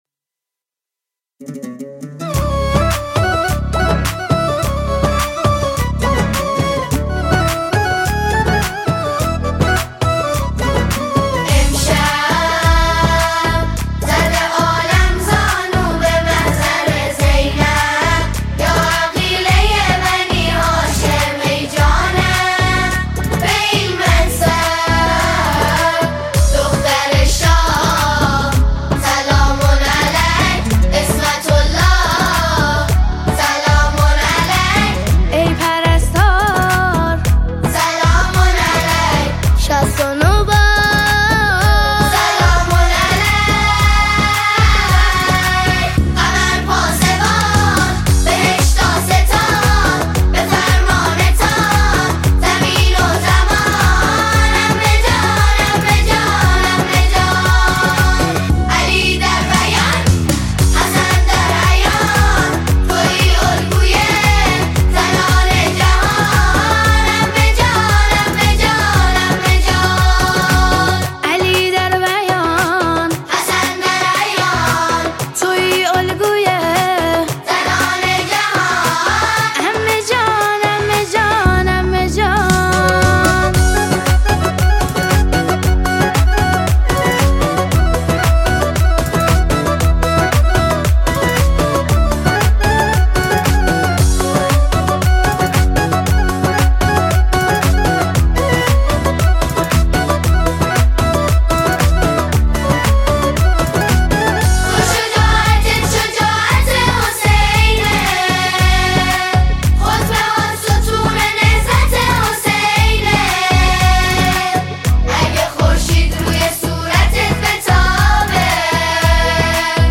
اثری پرشور، کوبنده و عمیقاً احساسی
ژانر: سرود